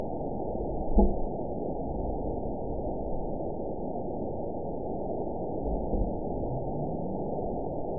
event 911601 date 03/05/22 time 03:26:42 GMT (3 years, 2 months ago) score 9.53 location TSS-AB02 detected by nrw target species NRW annotations +NRW Spectrogram: Frequency (kHz) vs. Time (s) audio not available .wav